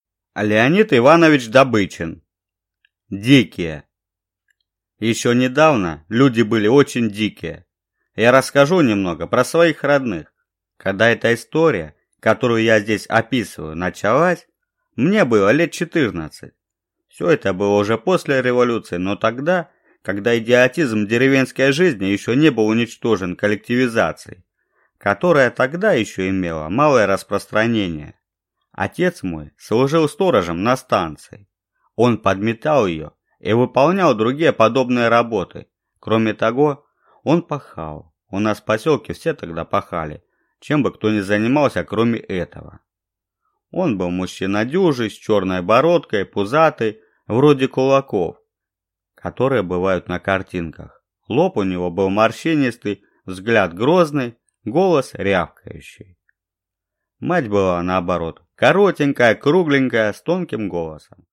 Аудиокнига Дикие | Библиотека аудиокниг